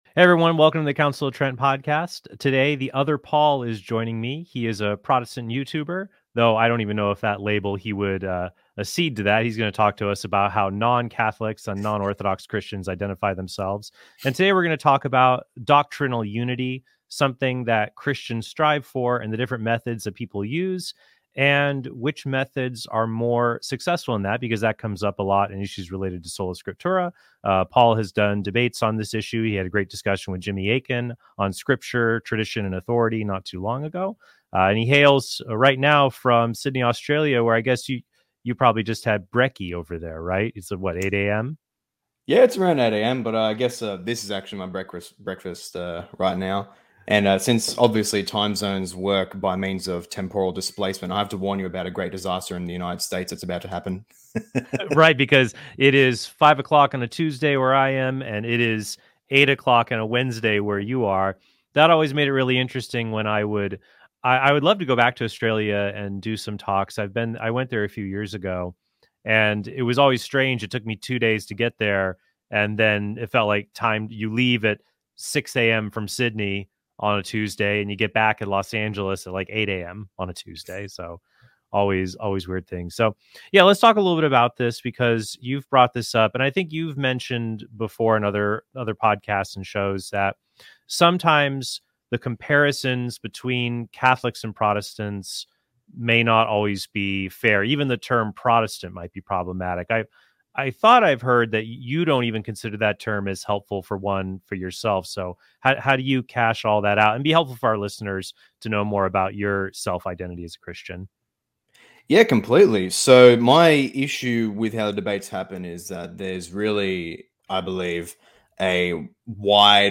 DIALOGUE: Are Catholics or Protestants More United?